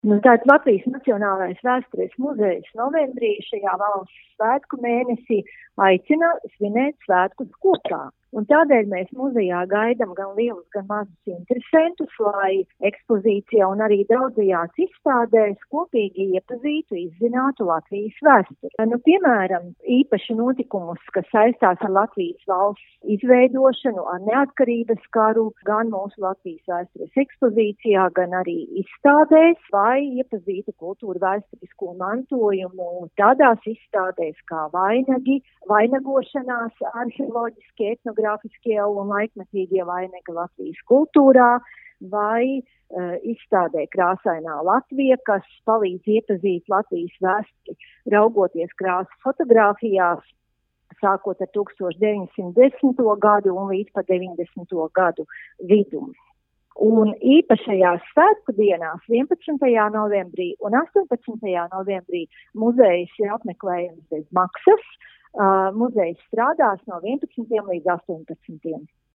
RADIO SKONTO Ziņās par Latvijas Nacionālā vēstures muzeja sarūpēto patriotu mēnesim